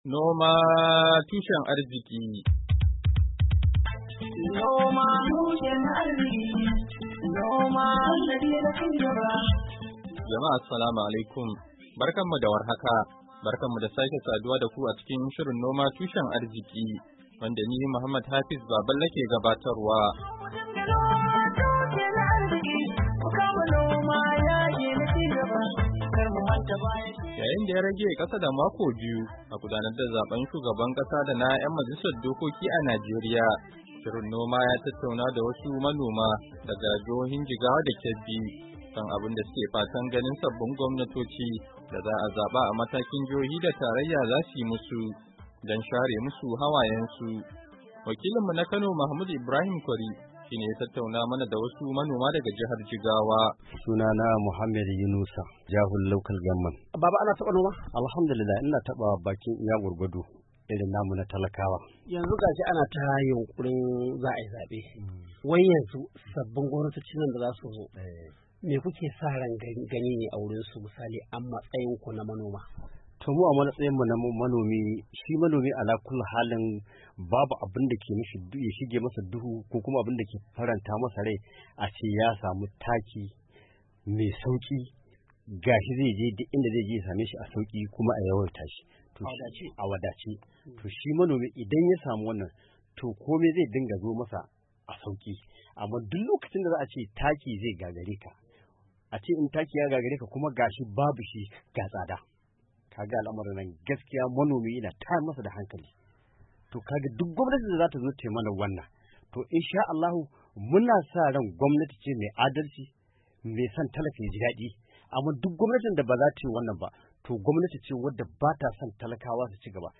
Shirin na wannan makon, ya tattauna da wasu manoma daga jihohin Jigawa da Kebbi, kan abun suke fatan ganin sabbin gwamnatoci da za’a zaba a Najeriya daga matakin jihohi da tarayya za su yi musu, don share mu su hawayen su.